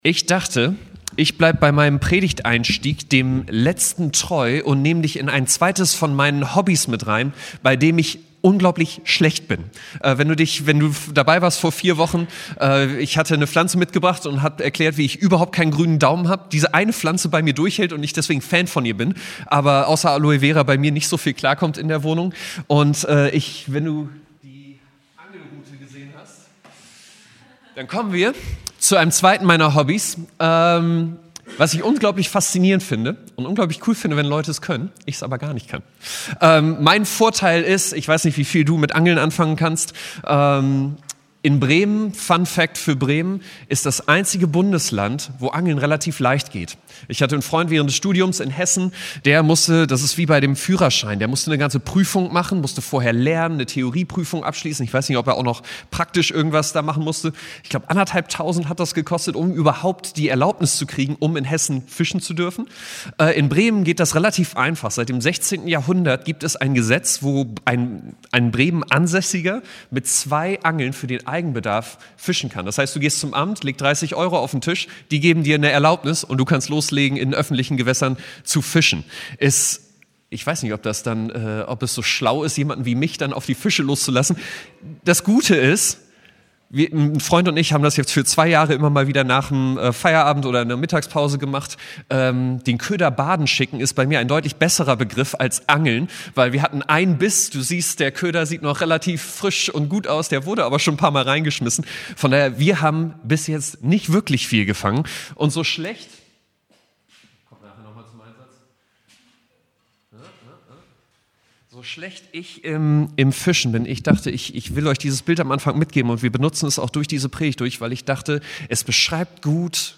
Serie: Standortgottesdienste